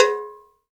Index of /90_sSampleCDs/Roland LCDP14 Africa VOL-2/PRC_Afro Toys/PRC_Afro Metals